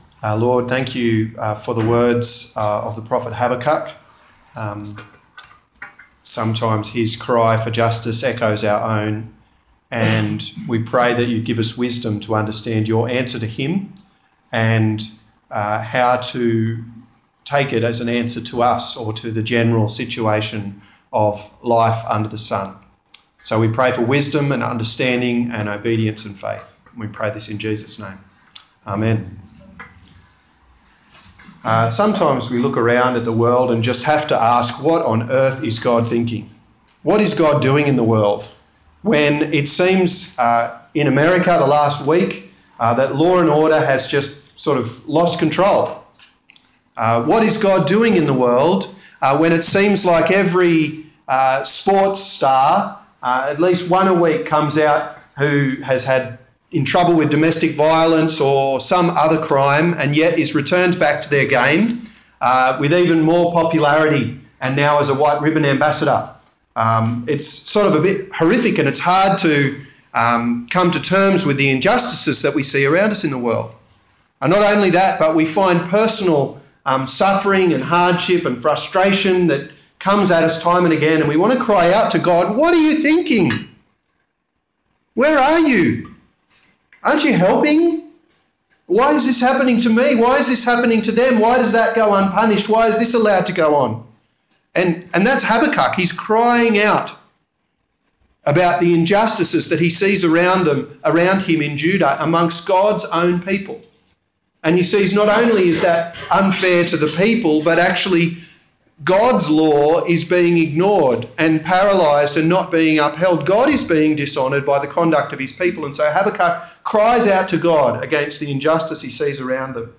10/07/2016 The Righteous will Live by Faith Preacher